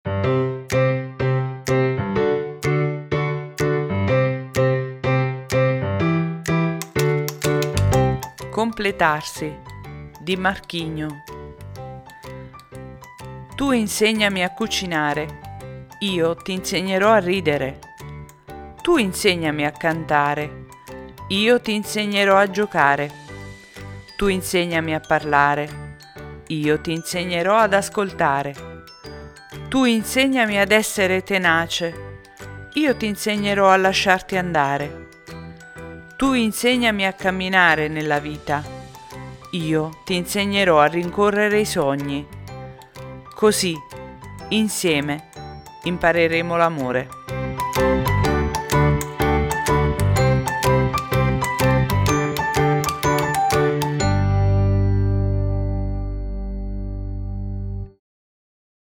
La poesia che vi leggo questa sera, è in tema con la festa di San Valentino in arrivo.